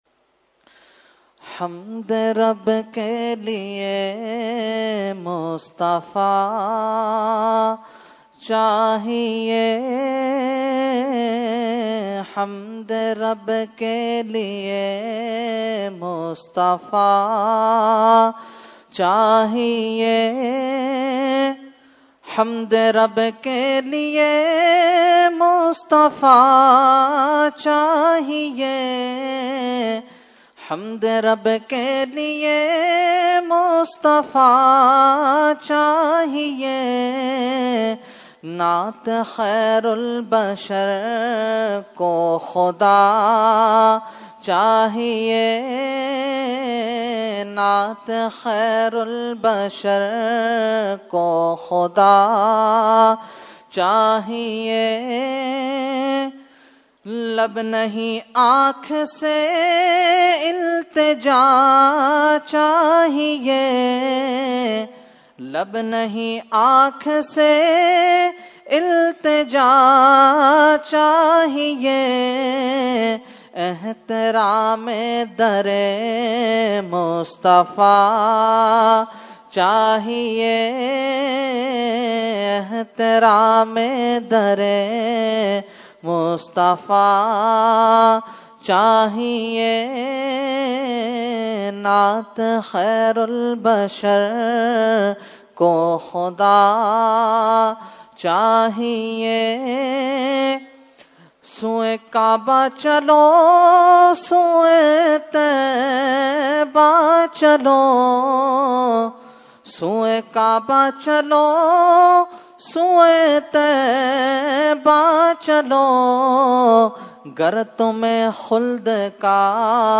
Category : Naat | Language : UrduEvent : Shab e Baraat 2018